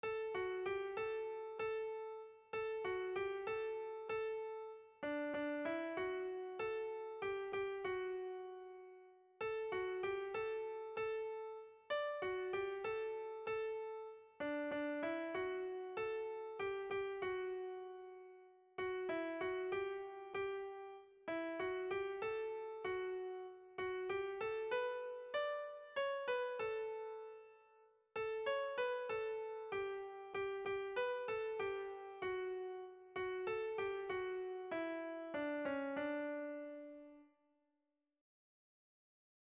Sentimenduzkoa
Zortziko handia (hg) / Lau puntuko handia (ip)
A1A2BD